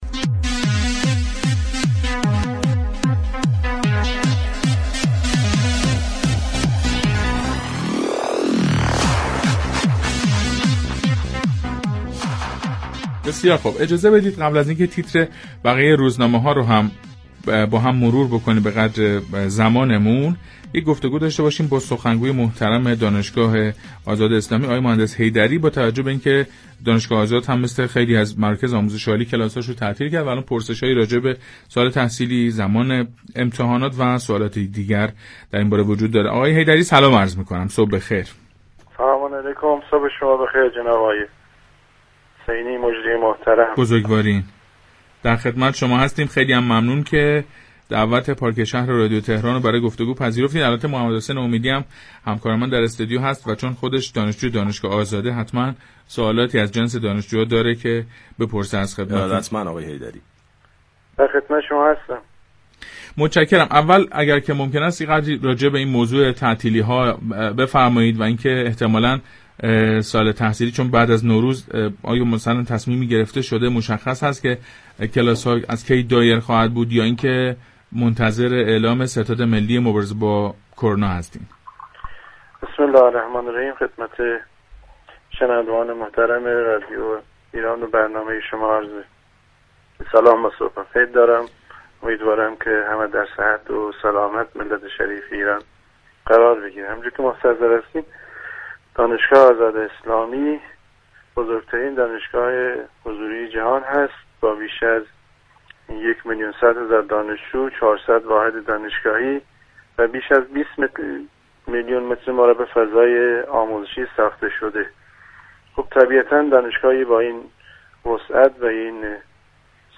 در گفتگو با پارك شهر